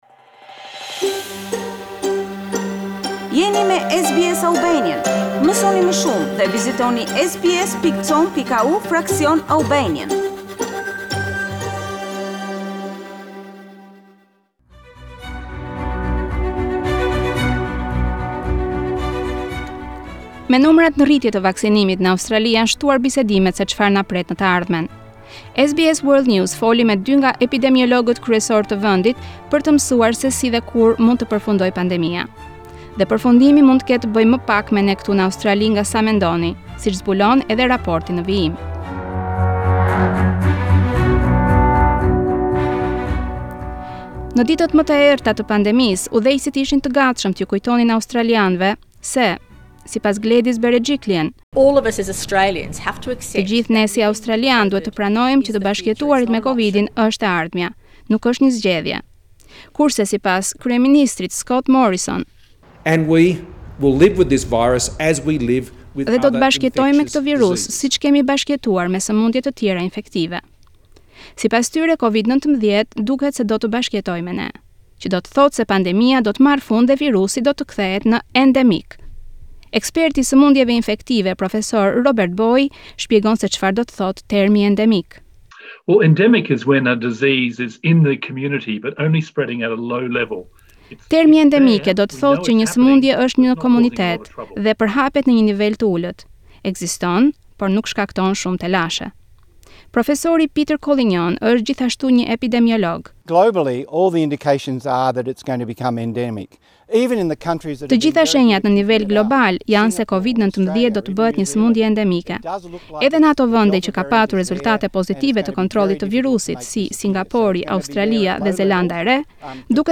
With Australia's vaccination rate still rising, the conversation is moving towards what's ahead. SBS World News spoke to two of the country's leading epidemiologists to find out how, and when, the pandemic might end.